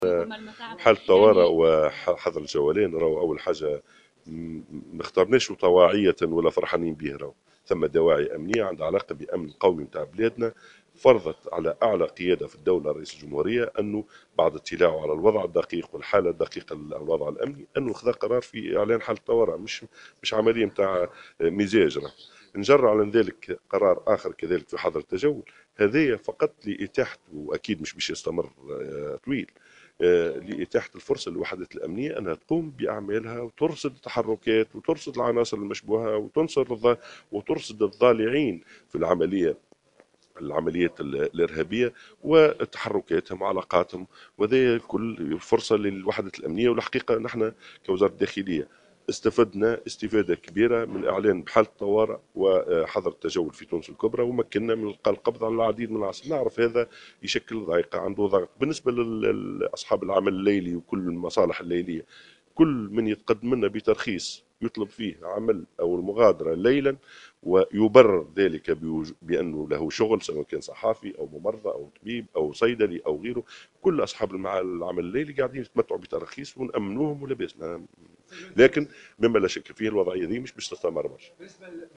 أكد وزير الداخلية الناجم الغرسلي في تصريح اعلامي اليوم الإثنين 30 نوفمبر 2015 أن اعلان حالة الطوارئ وحظر الجولان كان اضطرارا ولم تختره الدولة عن طواعية وليست سعيدة باتخاذه .